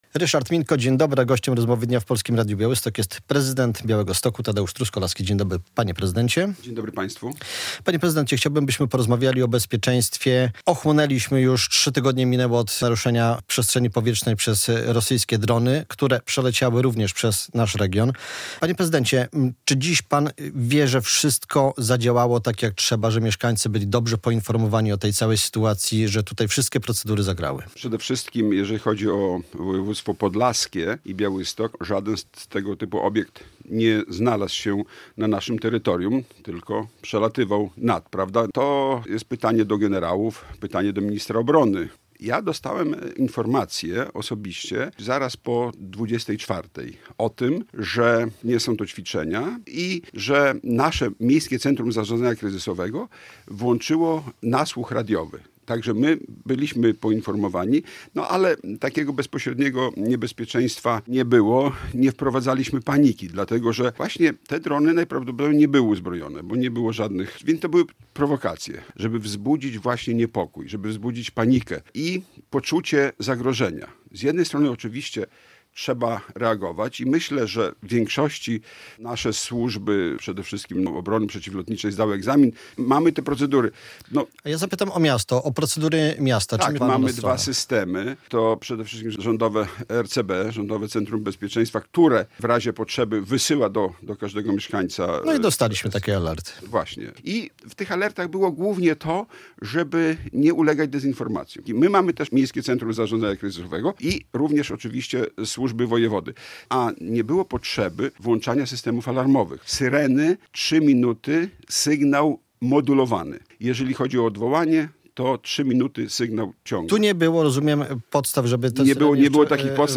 Prezydent Tadeusz Truskolaski w rozmowie w Polskim Radiu Białystok zapewnia, że miasto reagowało zgodnie z wypracowanymi procedurami po incydencie pojawienia się rosyjskich dronów nad regionem.